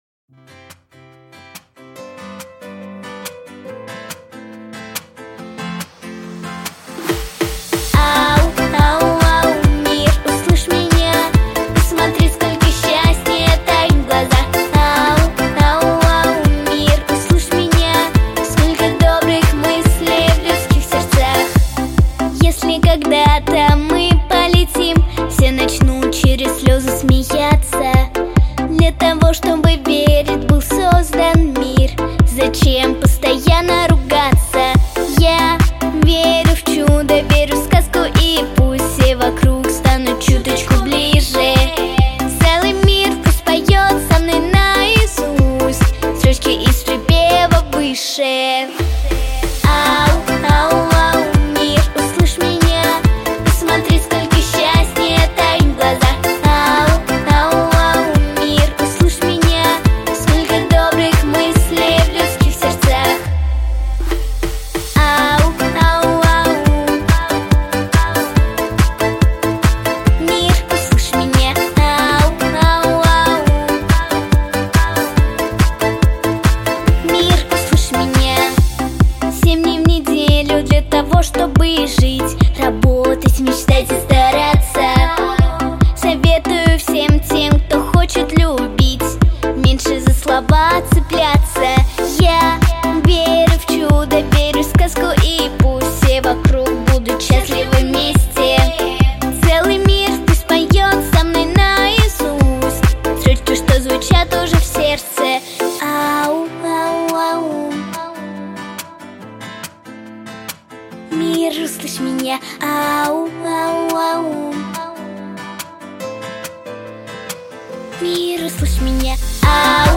• Категория: Детские песни / О близких людях